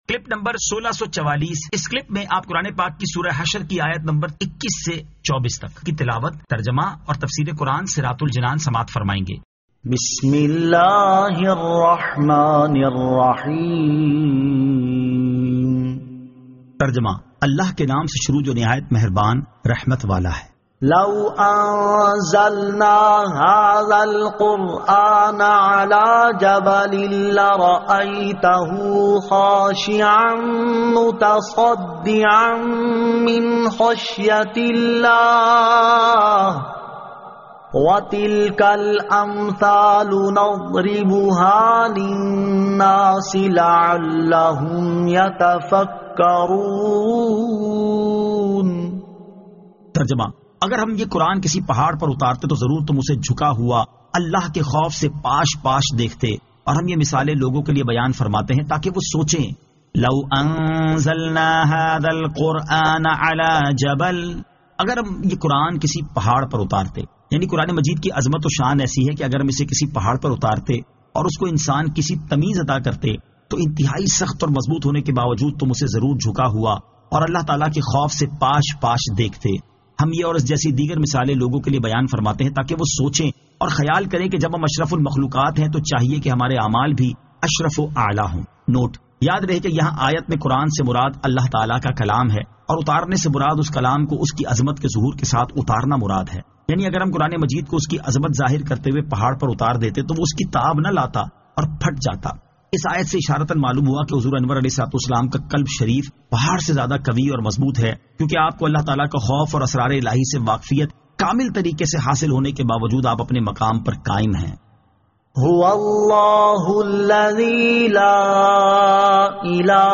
Surah Al-Hashr 21 To 24 Tilawat , Tarjama , Tafseer